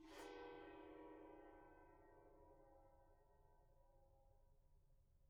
gongscrape_pp.wav